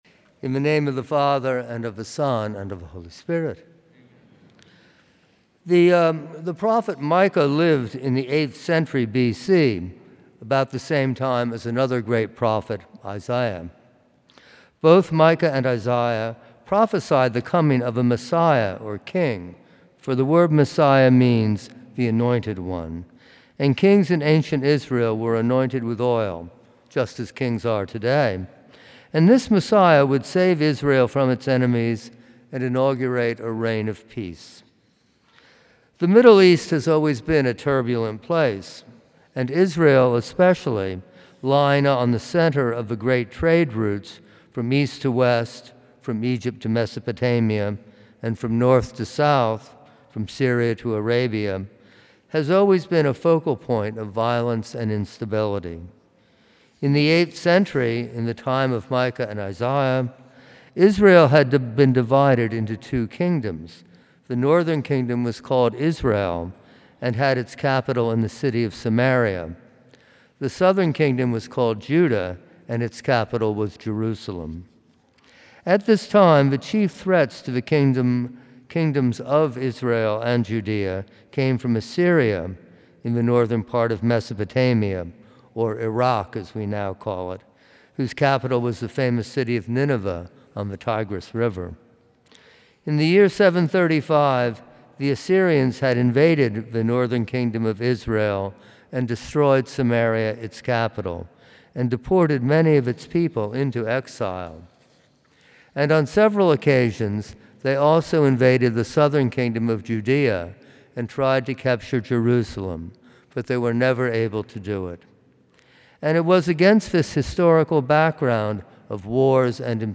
From Series: "Homilies"